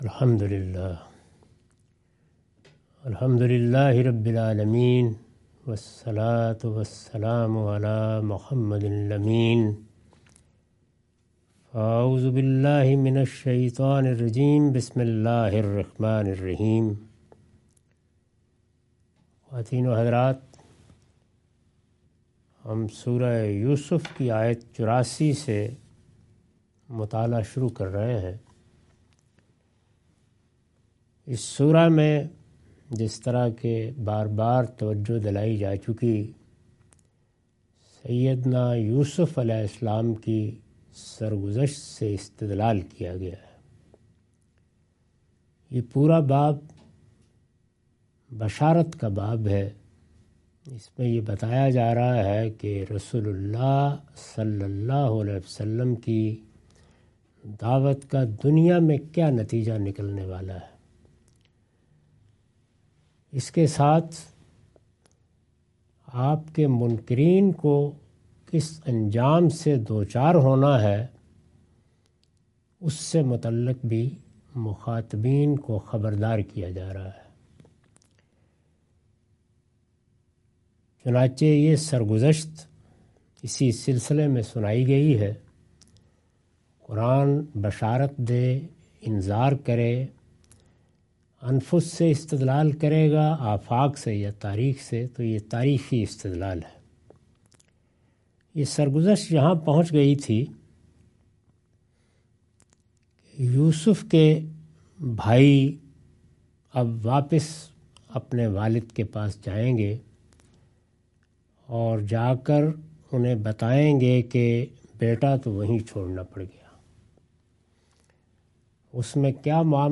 Surah Yousuf - A lecture of Tafseer-ul-Quran – Al-Bayan by Javed Ahmad Ghamidi. Commentary and explanation of verses 84-87.